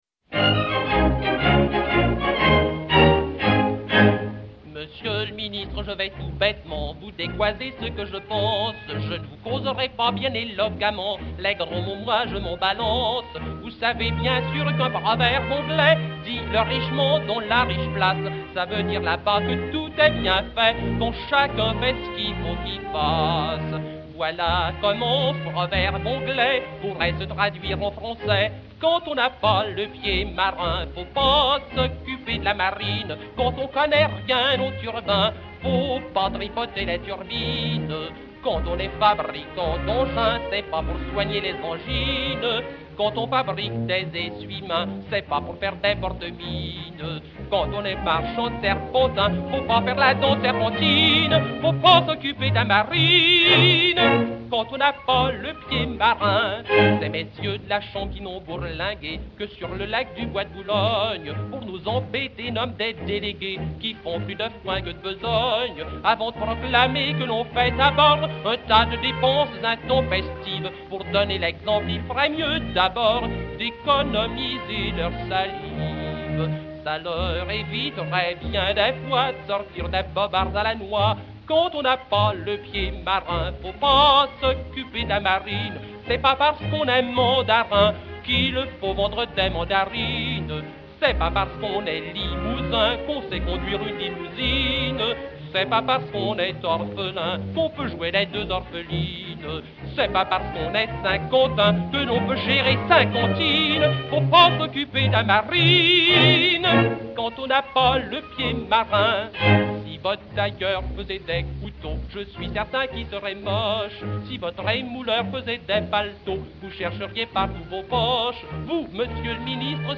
et Orchestre